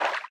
Sfx_creature_symbiote_swim_fast_06.ogg